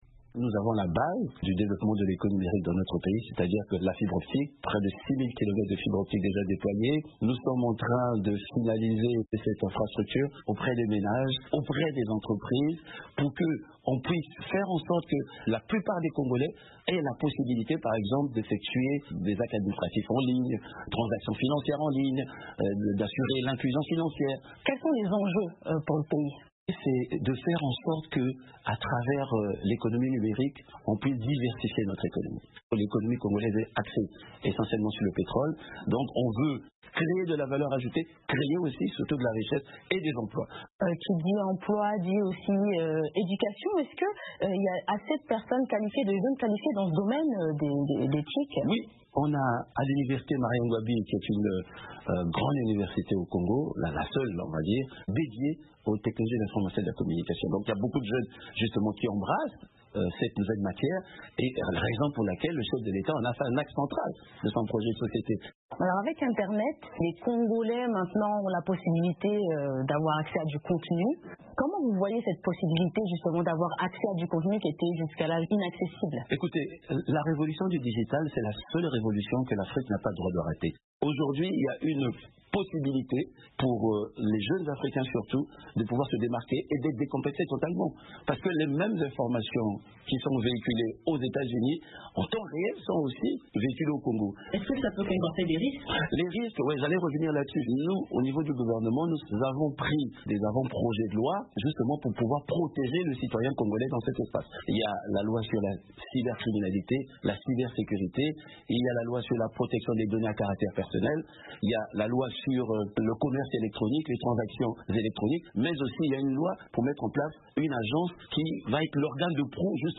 La République du Congo s’est engagée dans le développement du numérique. Elle entend en faire un levier majeur pour la diversification de l’économie. Entretien exclusif avec Léon Juste Ibombo, le Ministre des Postes, des Télécoms et de l’Economie numérique.